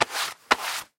Звук перчаток протирающих локти